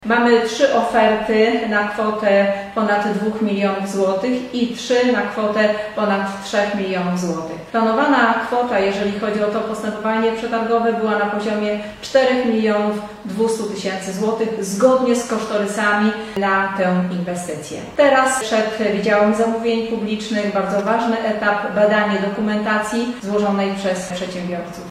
– Cieszymy się ogromnie, bo wszystkie oferty mieszczą się w kwocie, którą przeznaczyliśmy na remont – informuje Danuta Madej, burmistrz Żar: